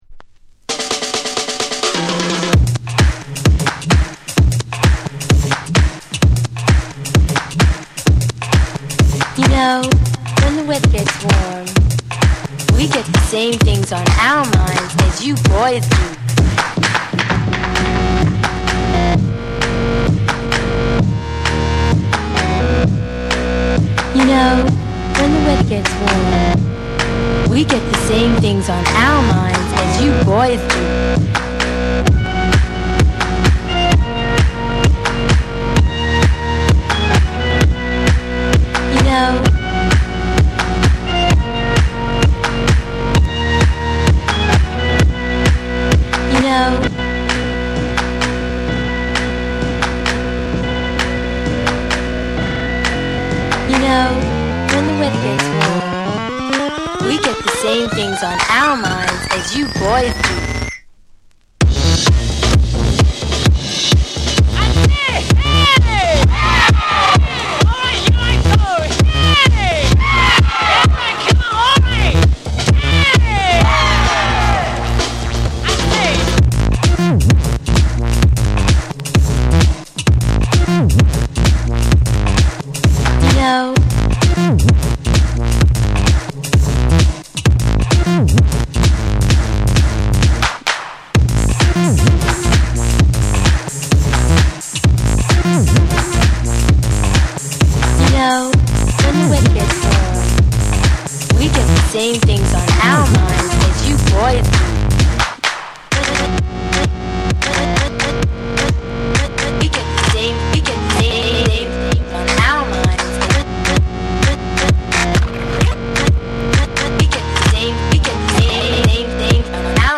グリッチ感のあるビートメイクとジャジーで遊び心にあふれたメロディが絡み合う、独特の世界観が光る作品。
BREAKBEATS